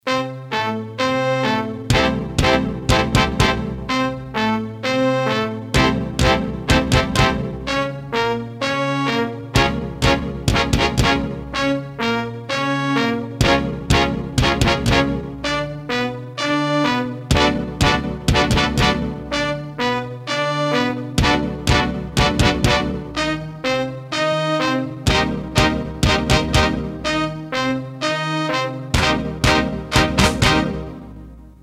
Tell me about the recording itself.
Tags: Princeton Hockey Baker Rink